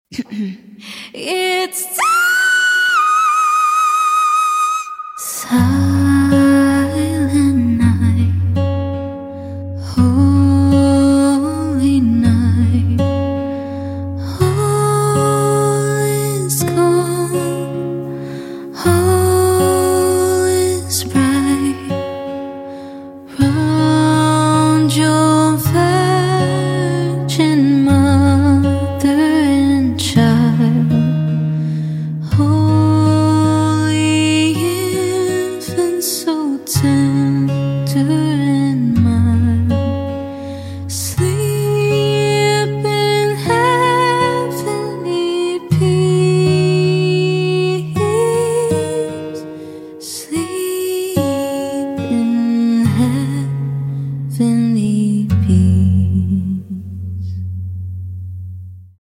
On another note (hehe), this was also my first time playing the guitar!!
I actually don’t know how to play any instruments and usually use YT instrumentals but this was SO easy to learn y’all so I hope you guys enjoy this lil xmas cover!!!
Apart from the guitar sound and fingerpicking pad, it also has a piano feature, drum machine and bass!!!